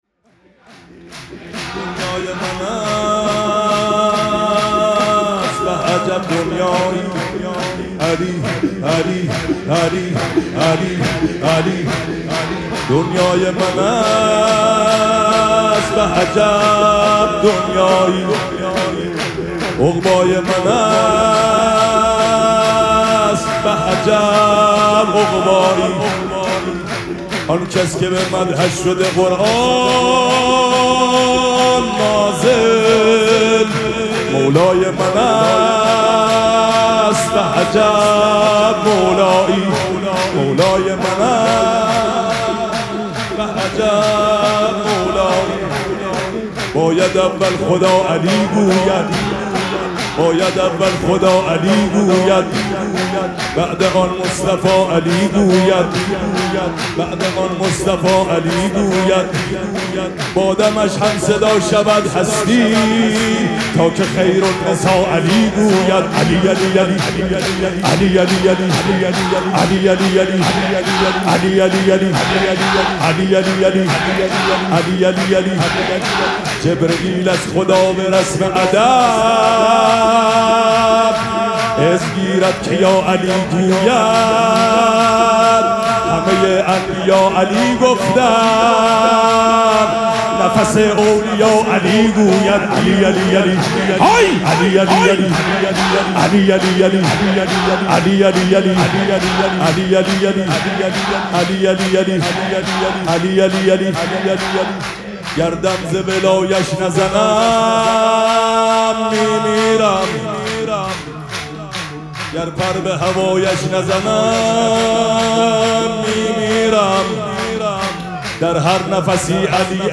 مراسم جشن ولادت حضرت امیرالمؤمنین علی علیه السلام
سرود
مداح